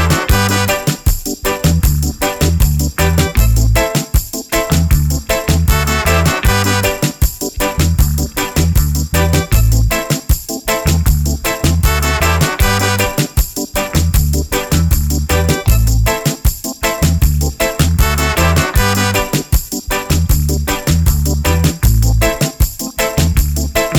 no Backing Vocals Reggae 3:29 Buy £1.50